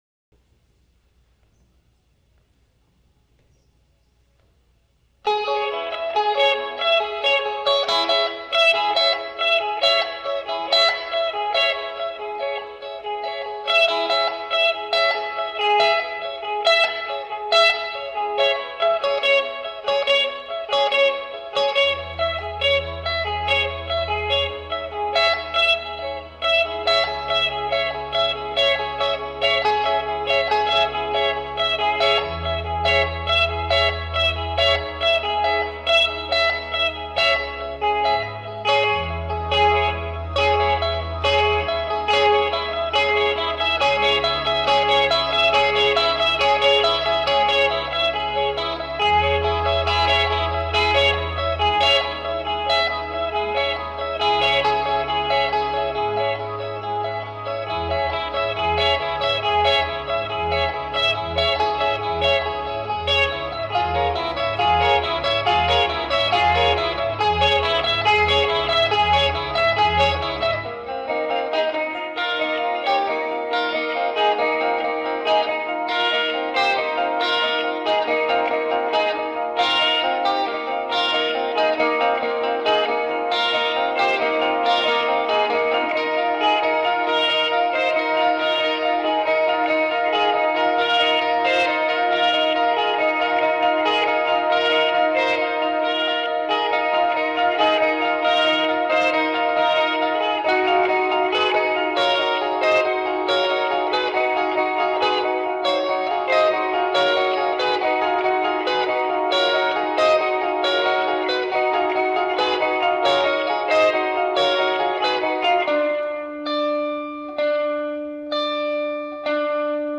Santa Cruz performance, 1/26/86
(dub from cassette, 2/05)
electric guitars.